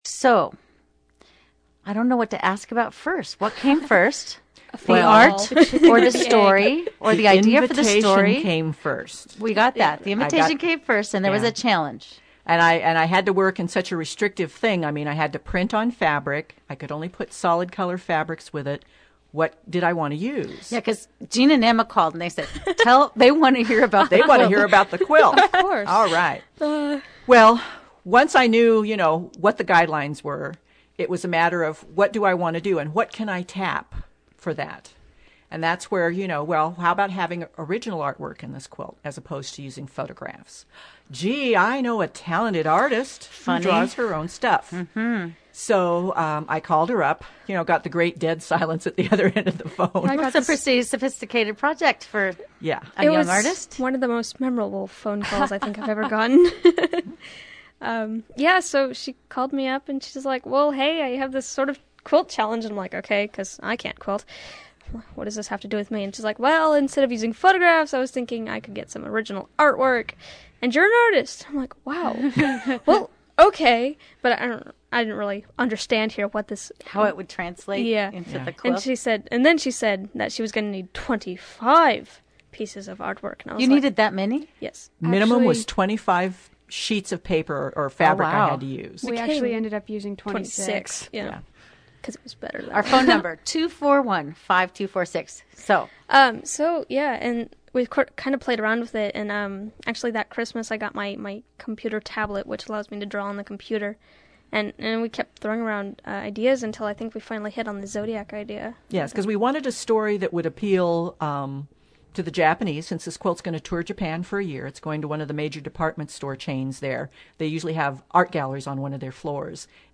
The I-5 Live show was a one hour live program that covered anime and quilting, with everyone there in the studio.
Here are MP3 files of the I-5 Live interview, broken into 5 parts because of their size.